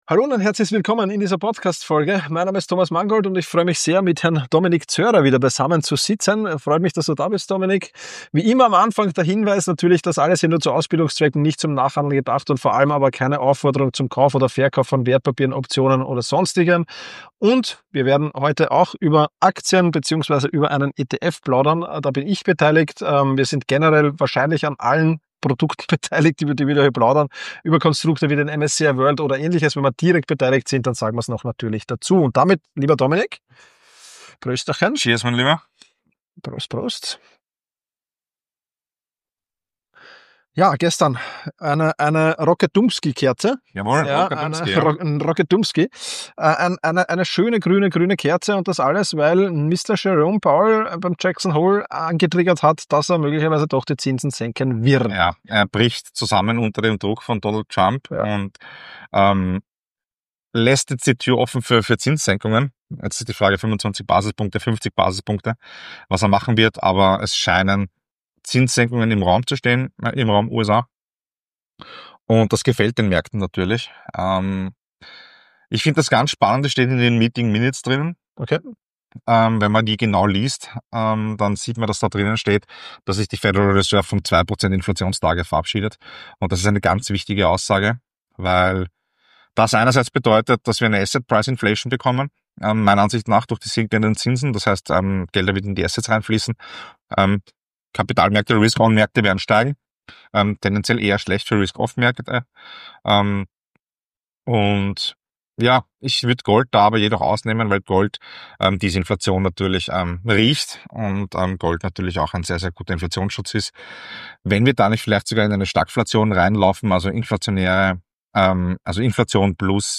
Natürlich werfen die beiden auch einen Blick nach Fernost und sprechen darüber, weshalb China aus ihrer Sicht ein spannender Zukunftsmarkt bleibt. Besonders im Fokus: der KWEB-ETF auf chinesische Internetaktien, Möglichkeiten und Risiken des Investments und der Einfluss der US-Politik auf globale Technologien. Abgerundet wird die Folge mit einer lebhaften Diskussion zu den Themen künstliche Intelligenz, die Rolle von Nvidia und der aktuellen Blasenbildung auf dem Markt, sowie staatlichen Eingriffen wie der US-Beteiligung an Intel.